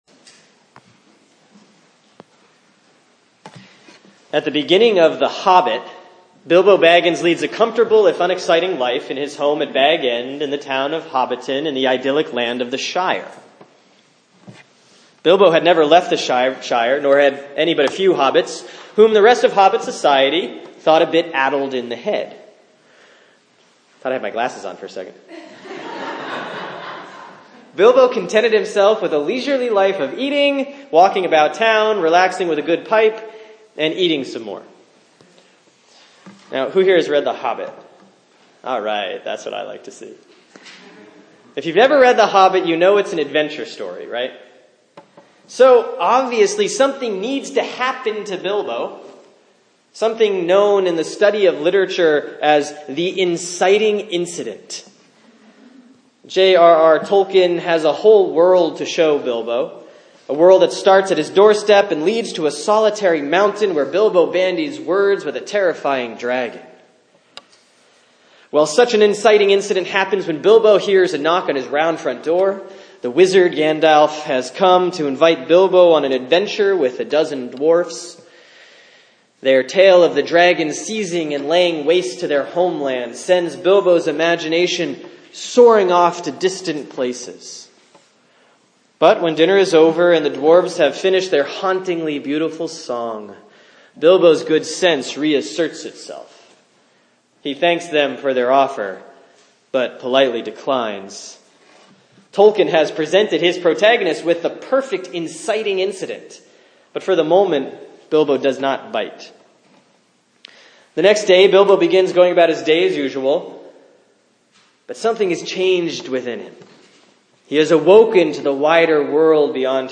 Sermon for Sunday, May 1, 2016 || Easter 6C || John 5:1-9